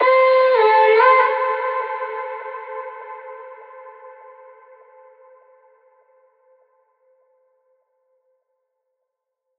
VR_vox_hit_calling_Amin.wav